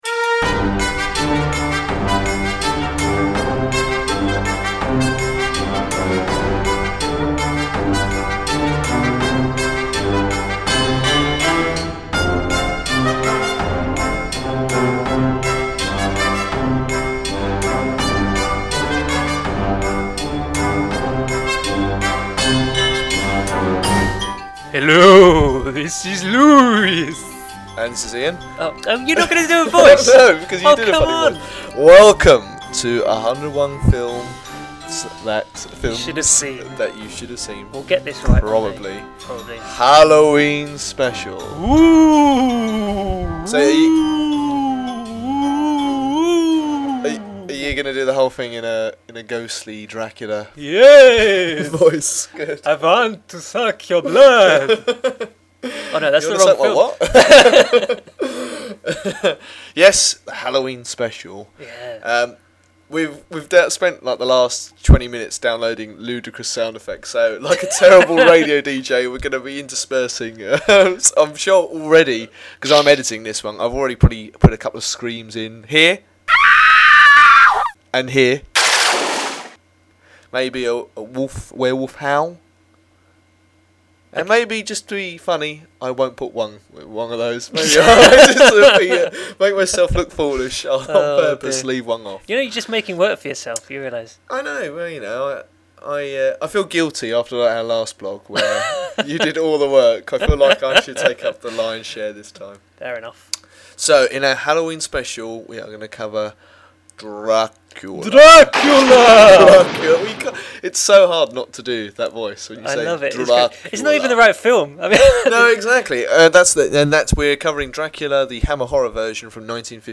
Plus, you want to hear some cheesy sound effects?